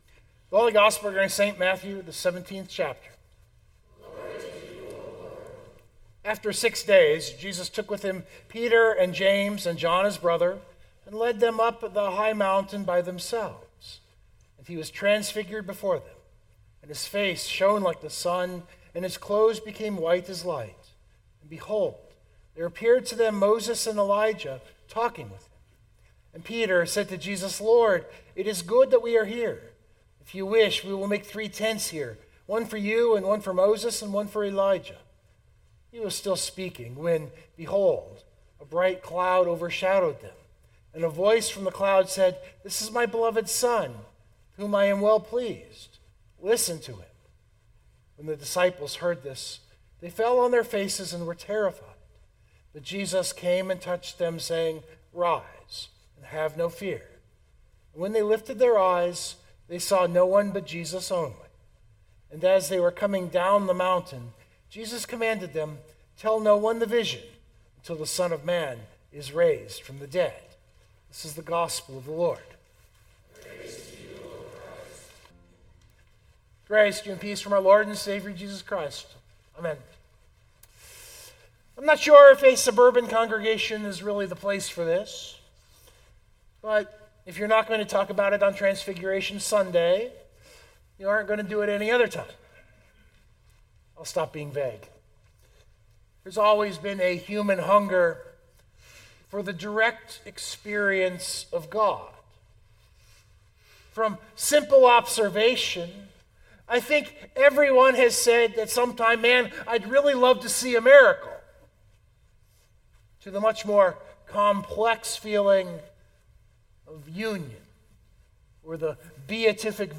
- Father, Pastor, Reader, Numbers Geek, Coffee Addict